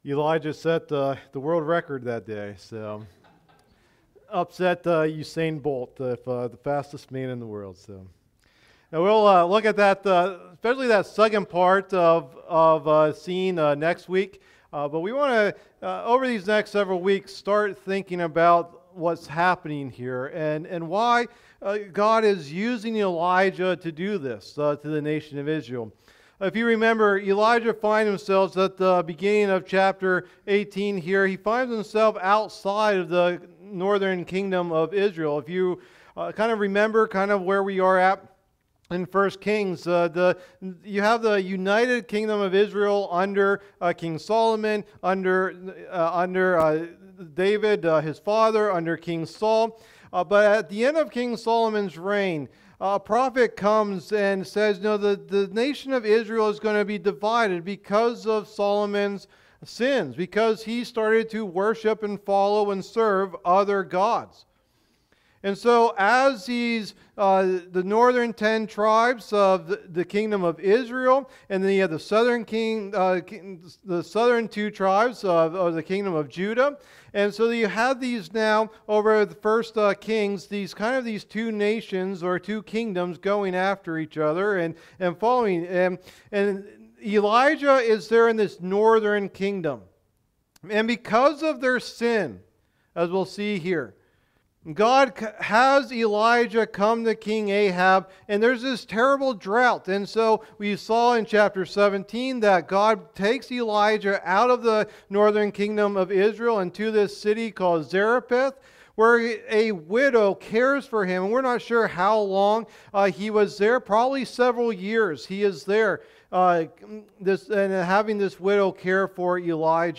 Message #6 of the "Life of Elijah" tecahing series